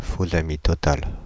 Ääntäminen
Synonyymit faux-ami absolu Ääntäminen France (Île-de-France): IPA: [fo.za.mi tɔ.tal] Haettu sana löytyi näillä lähdekielillä: ranska Kieli Käännökset englanti total false friend Suku: m .